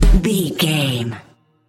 Aeolian/Minor
synthesiser
drum machine
hip hop
Funk
neo soul
confident
energetic
bouncy
funky